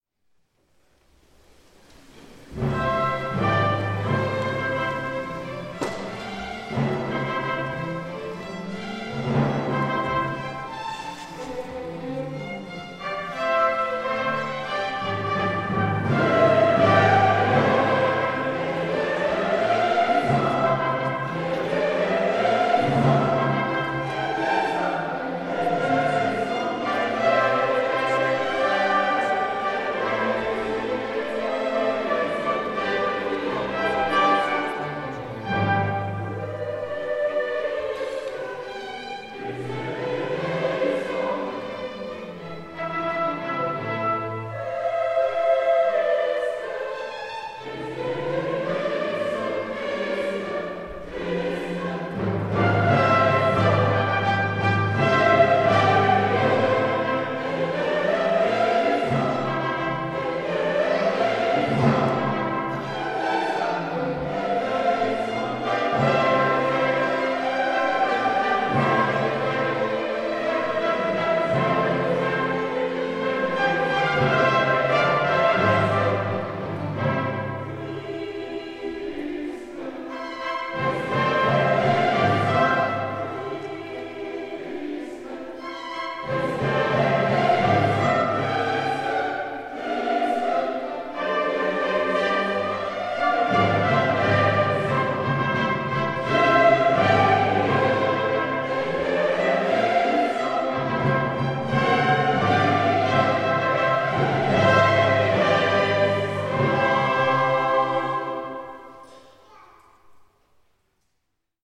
(Weihnachten)